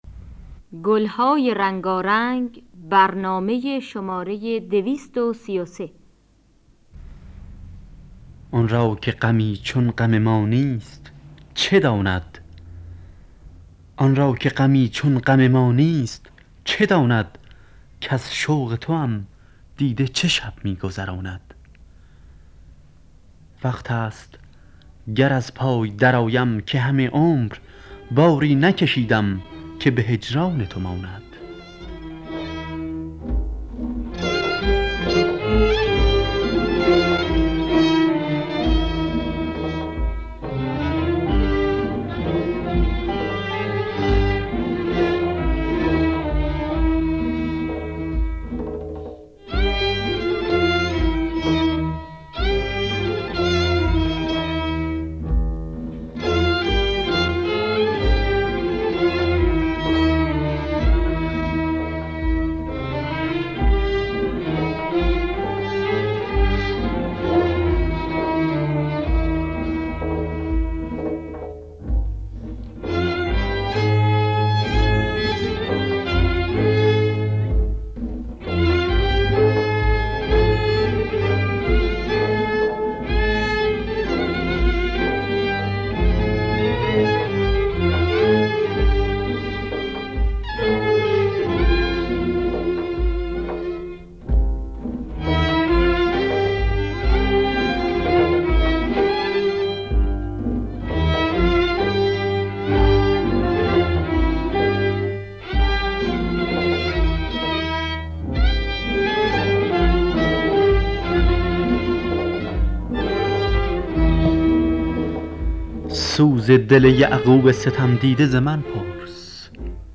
در دستگاه سه‌گاه